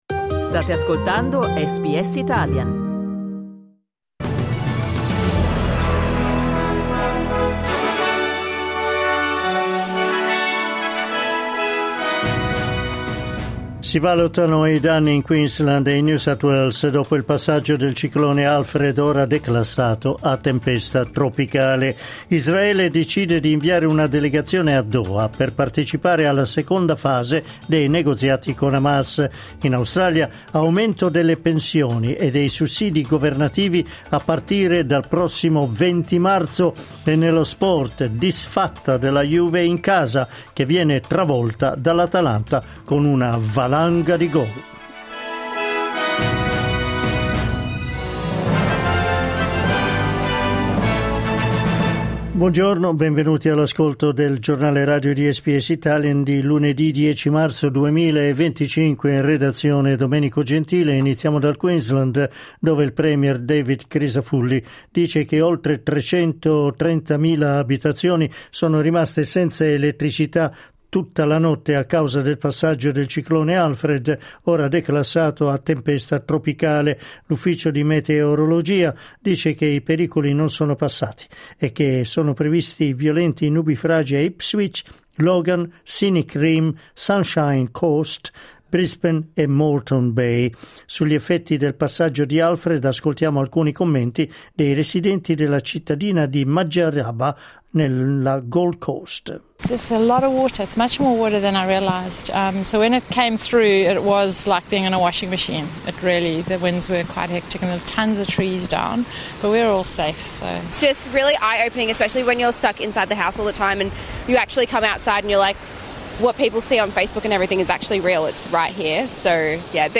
Il notiziario di SBS in italiano.